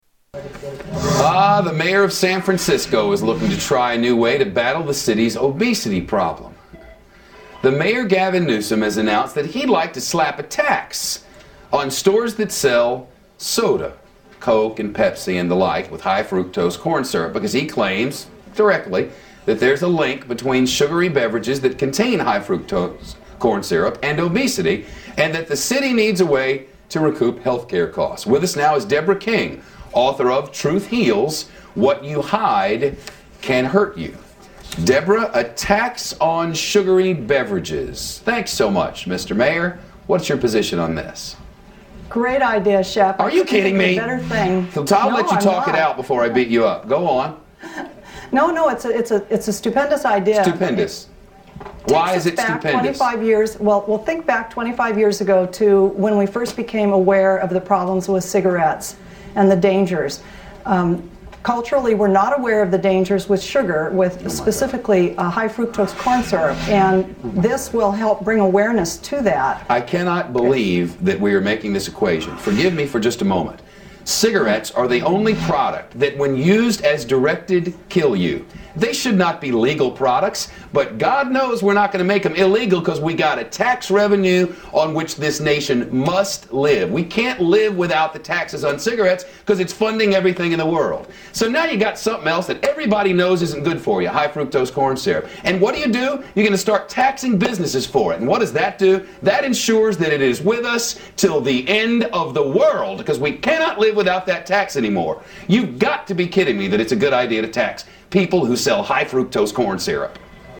Tags: Media Shepard Smith News Anchor Shepard Smith The Fox Report News Anchor